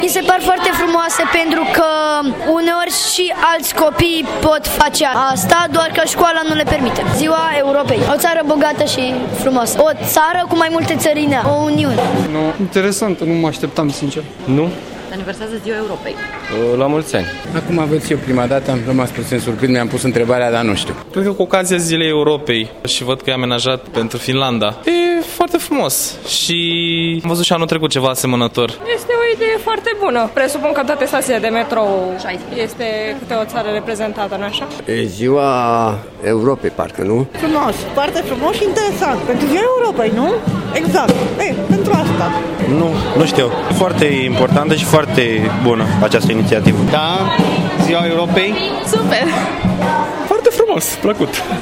vox-calatori-8-mai.mp3